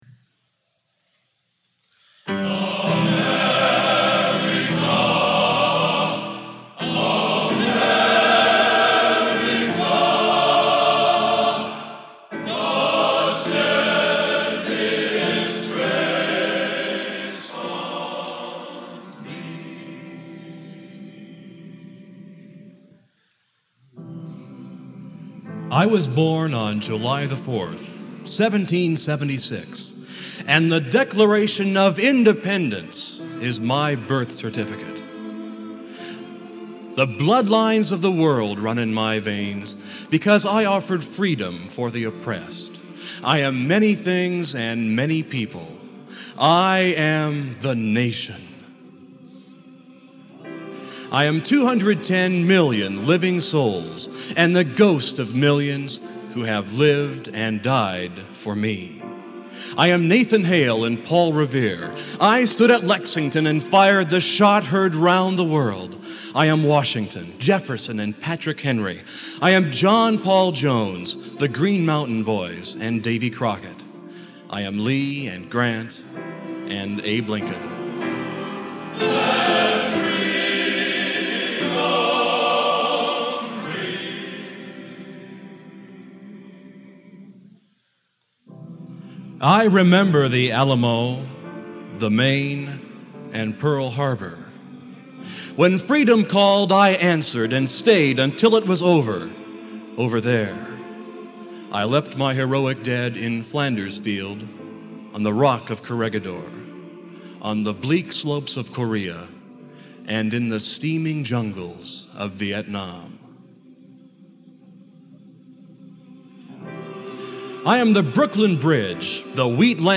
Genre: Original Composition Patriotic | Type: